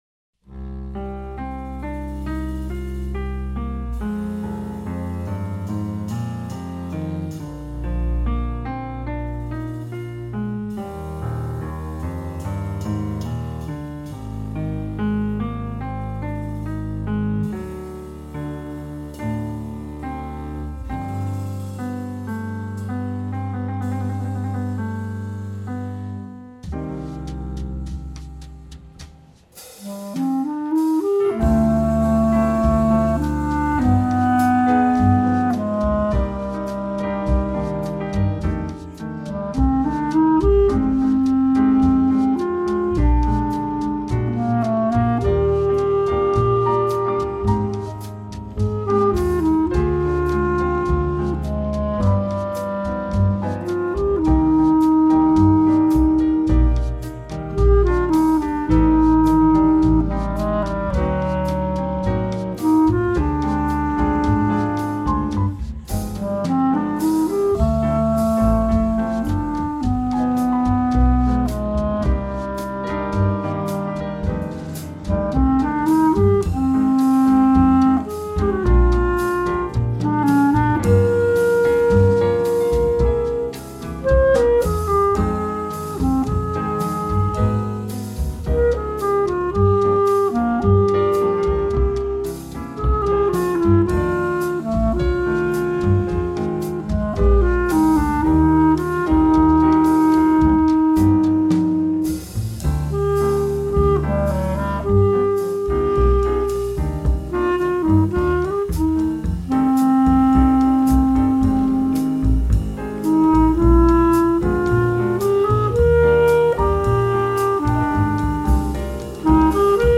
セッティングは、下記の通りで、カラオケCDの楽譜を、曲を聴きながら演奏して、あとで重ねました。
録音場所 カラオケ
録音環境 楽器から30cmぐらいで録音
（やさしく演奏バージョン）
そして、この演奏は、あえて全編「やさしく」吹いてみました。
リードがしっかりしているので、やさしく吹いても、そして開放系の喉音でも音がぶれにくいですね。
やさしく余裕がありそうな雰囲気は出ているけれど、逆に「リードが鳴っている」感じは少ないですね。
ちょっとのっぺりとしちゃうというか。